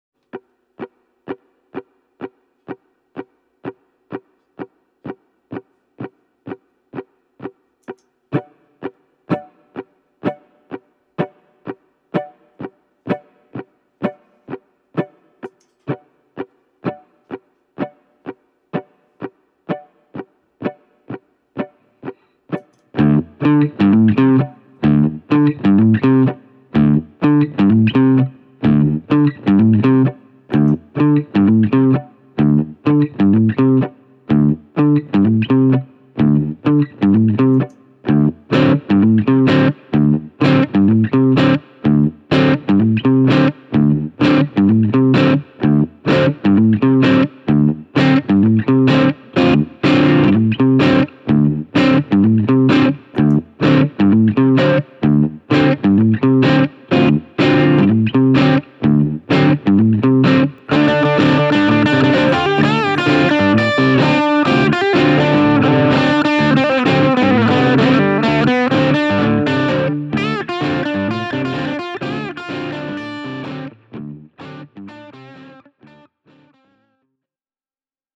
Myös soundin puolesta Ditto on hyvin kiitettävällä tasolla, sillä se toimittaa kompressoimatonta audiota 24-bittisellä resoluutiolla.
Esimerkkipätkässä rakennan itselleni taustan, jonka päälle voi harjoittaa yhden tutun kappaleen soolo-osuuksien soittoa:
tc-electronic-ditto-looper.mp3